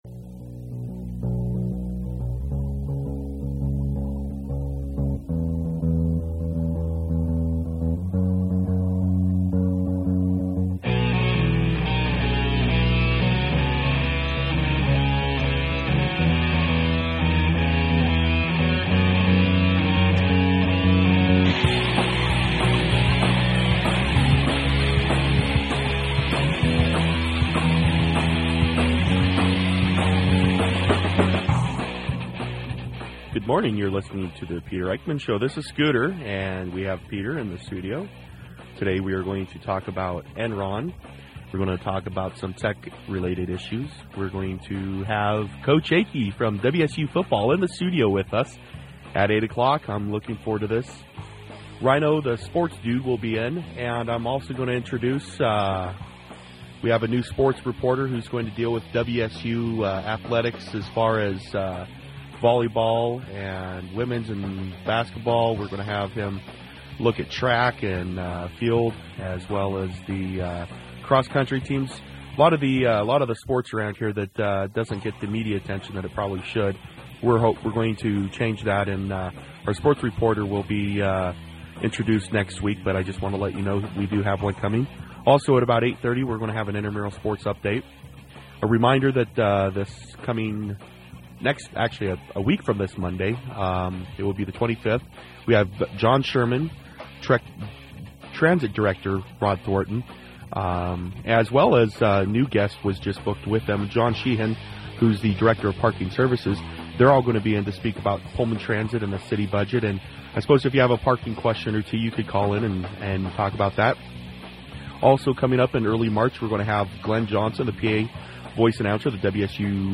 Today's radio show topics: Enron Tech News